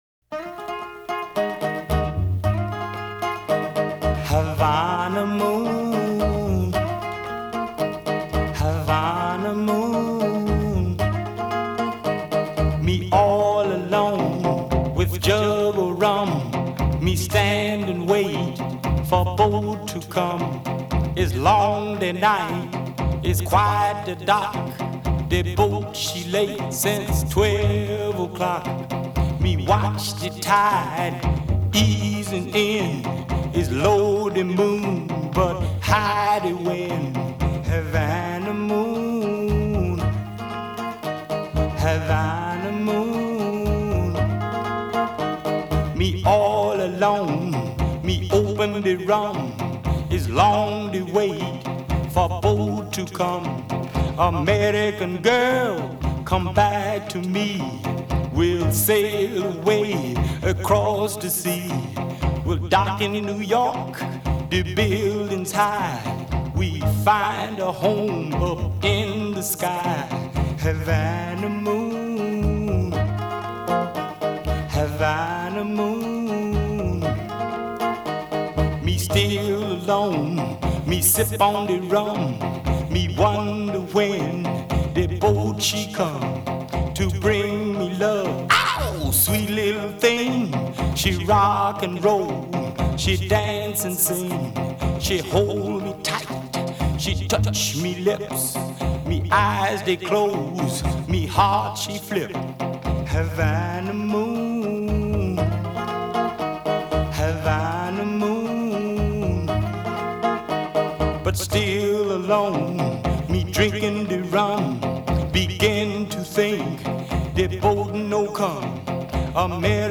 Рок-н-ролл